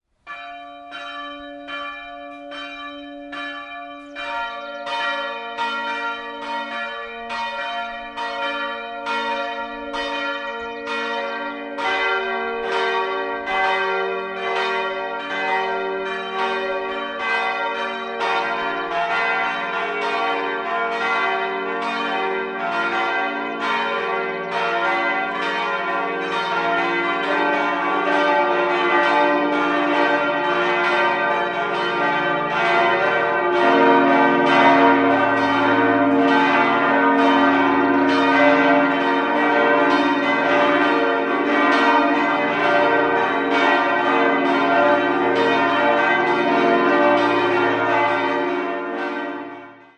6-stimmiges erweitertes Salve-Regina-Geläute: b°-d'-f'-g'-b'-d'' Alle Glocken wurden 1962 von der Gießerei Hofweber in Regensburg hergestellt.